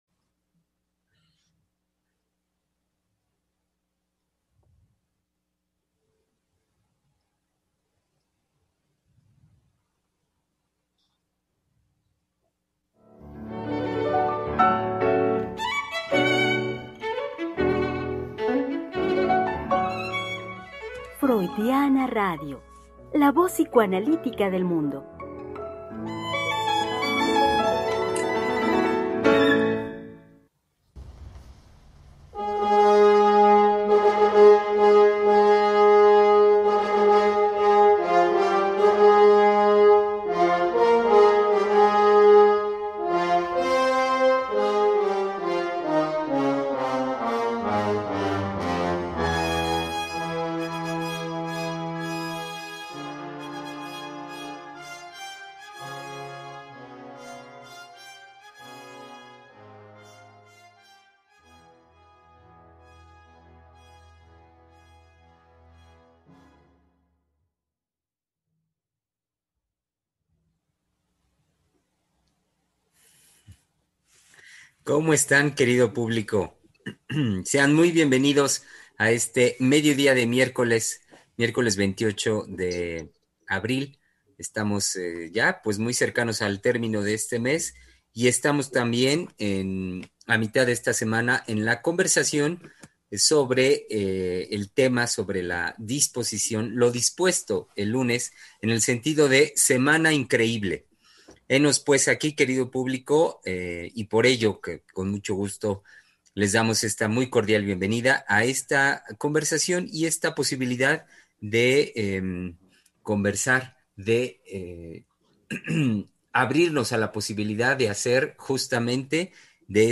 Programa transmitido el 28 de abril del 2021.